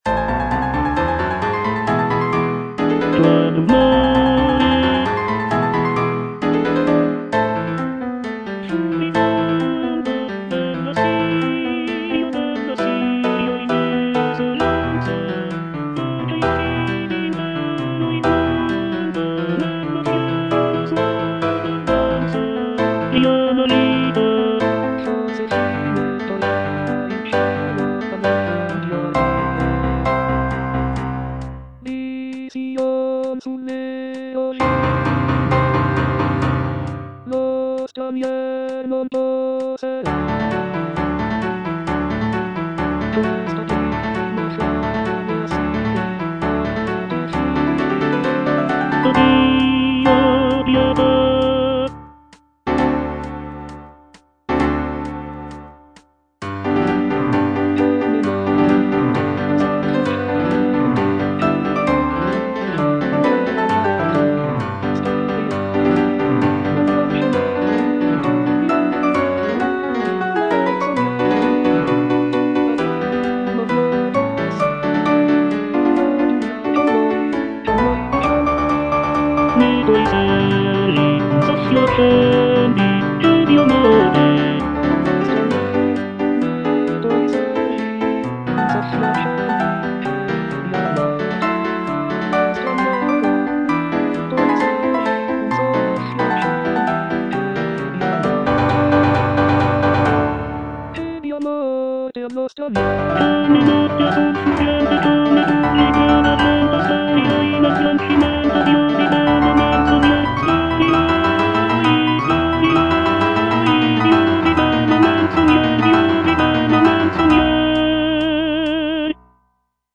G. VERDI - DI LIETO GIORNO UN SOLE FROM "NABUCCO" Qual rumore (tenor II) (Voice with metronome) Ads stop: auto-stop